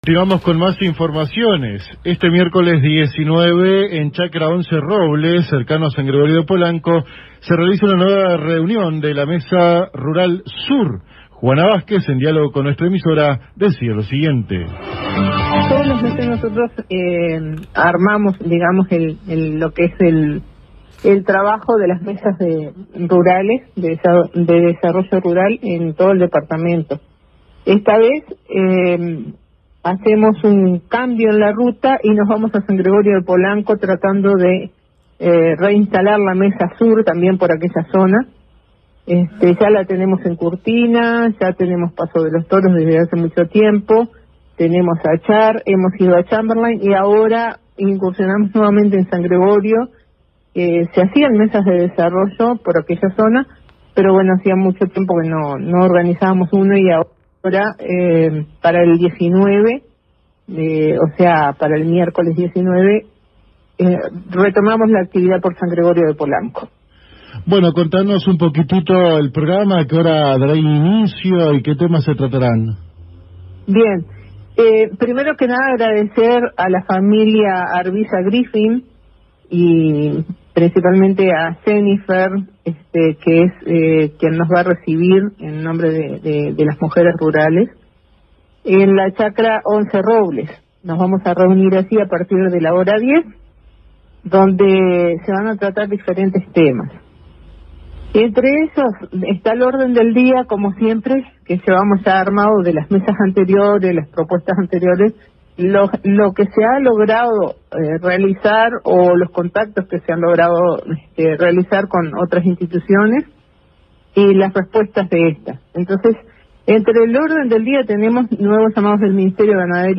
en diálogo con la AM 1110 de Paso de los Toros